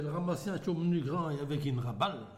Saint-Christophe-du-Ligneron ( Plus d'informations sur Wikipedia ) Vendée
Catégorie Locution